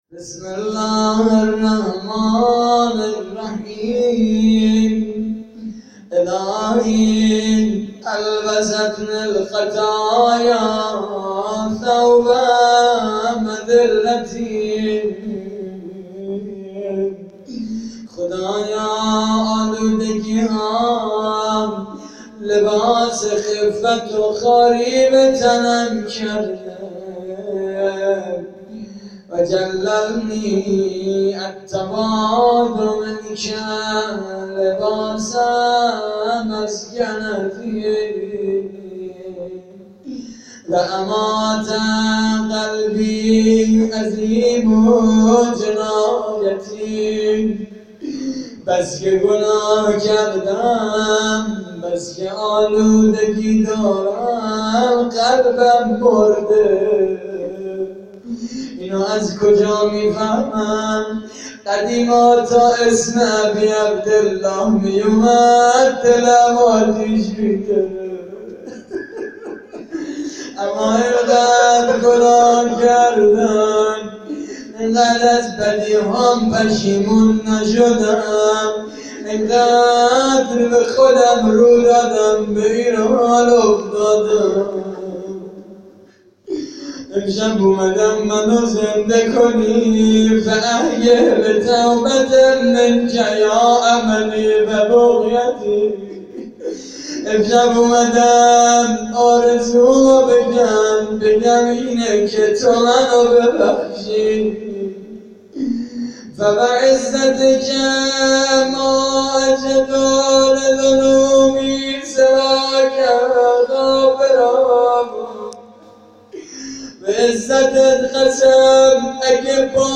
صوت مراسم:
مناجات: فرازی از مناجات تائبین؛ پخش آنلاین |